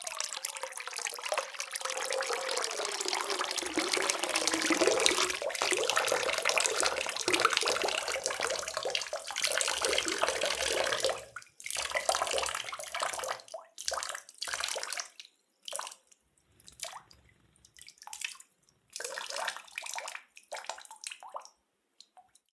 water-03
Category 🌿 Nature
bath bathroom bathtub bubble burp click drain drip sound effect free sound royalty free Nature